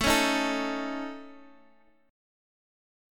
AMb5 chord